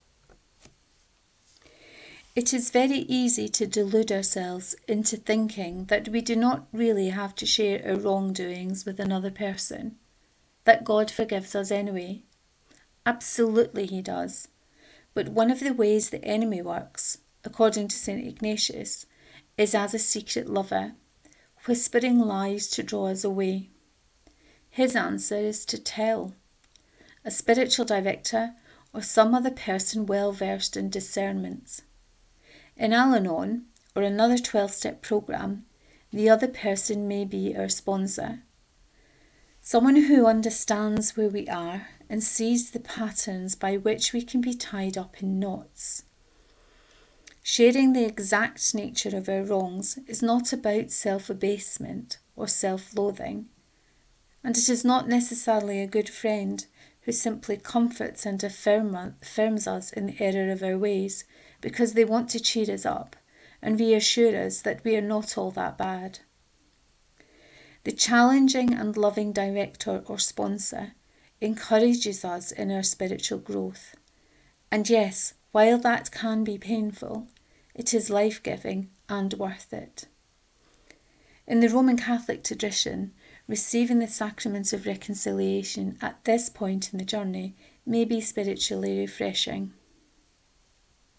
The Spiritual Exercises and The Twelve Steps 3, reading of this post.